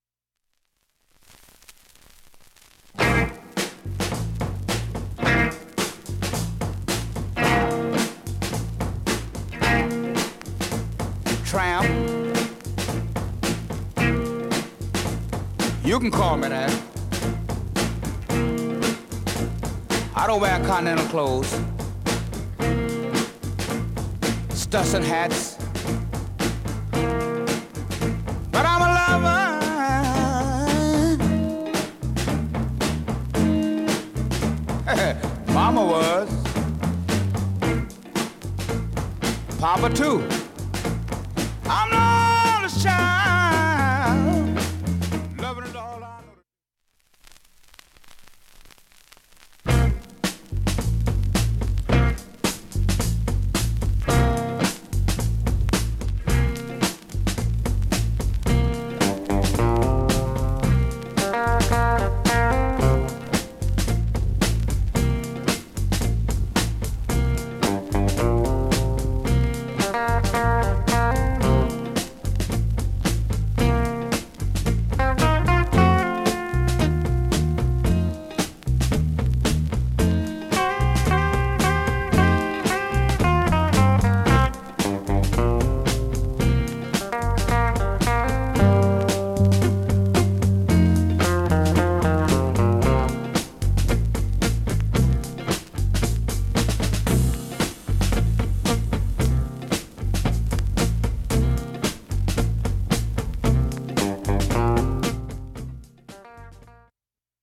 ◆少しサーフェスノイズ出ています試聴で確認ください。